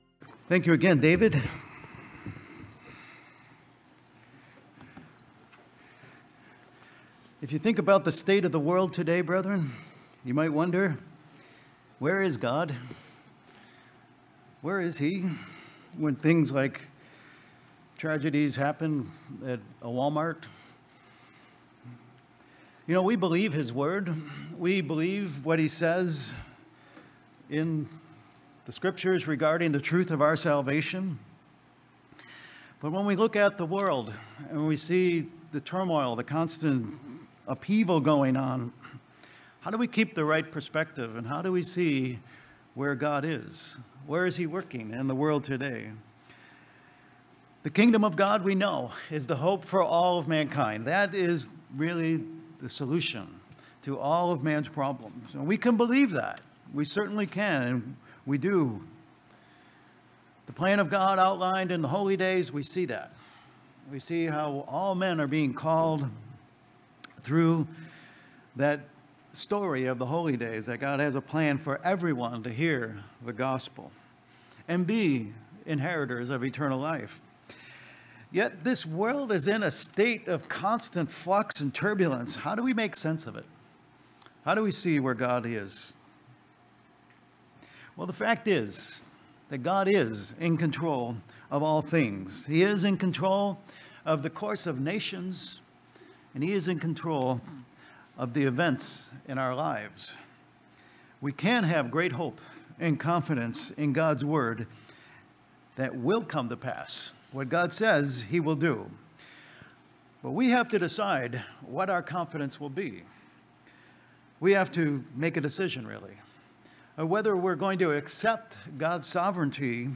Webcast Sermons
Given in Worcester, MA